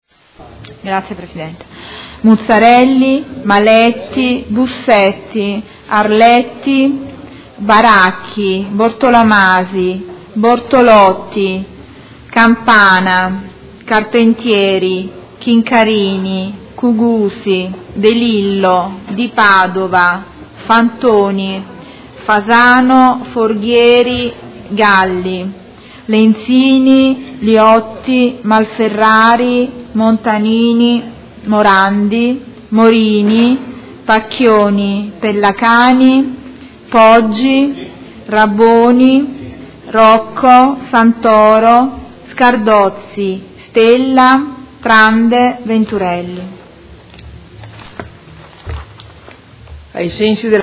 Seduta del 14/07/2016 Appello.
Audio Consiglio Comunale